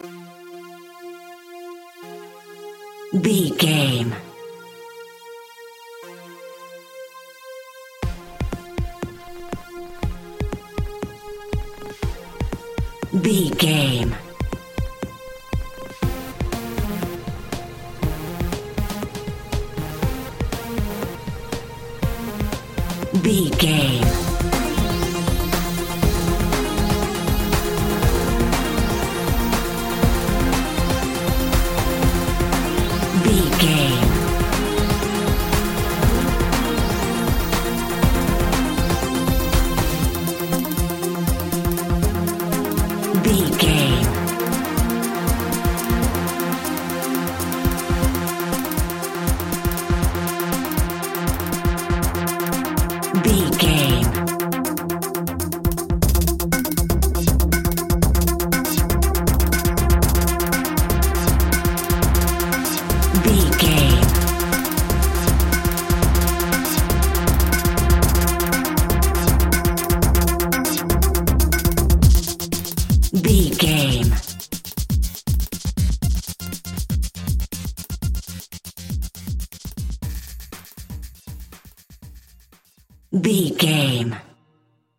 Skateboarding Music Cue.
Epic / Action
Fast paced
Aeolian/Minor
uplifting
futuristic
driving
energetic
repetitive
drum machine
electronic
techno
trance
synth lead
synth bass